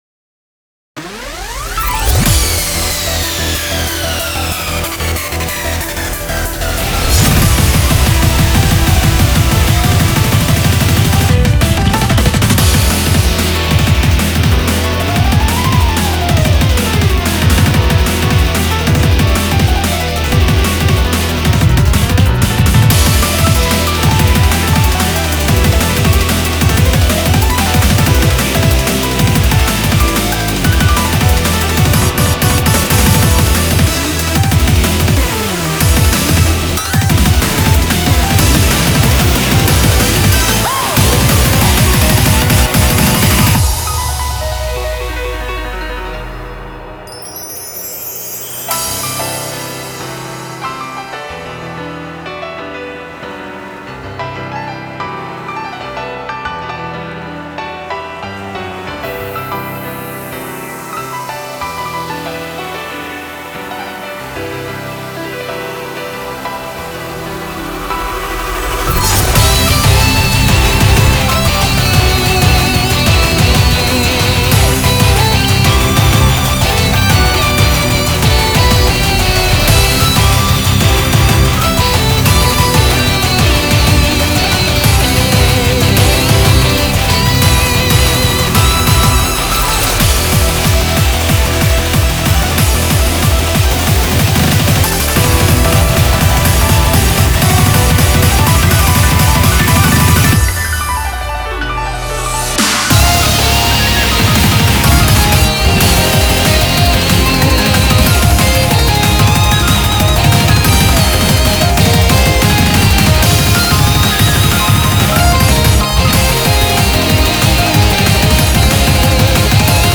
BPM186
Audio QualityPerfect (High Quality)